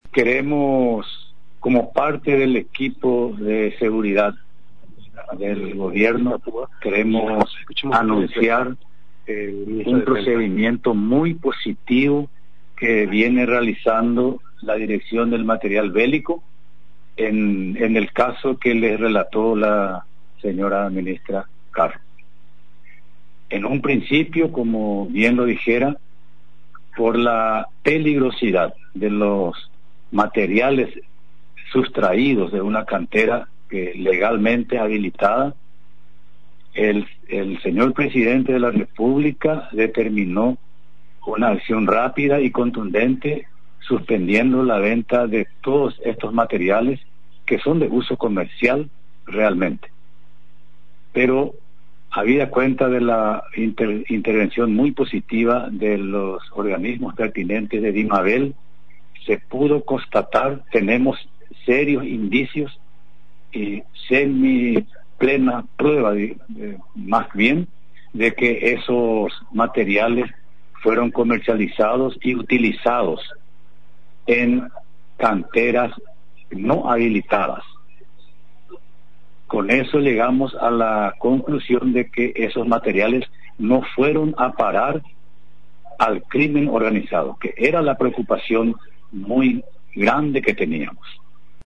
El ministro de Defensa, Oscar González, en rueda de prensa realizada en la residencia presidencial de Mburuvichá Róga, destacó la acción positiva de la Dimabel ante el robo de explosivos en la cantera habilitada, situada en la localidad de San Juan del Paraná, departamento de Itapúa.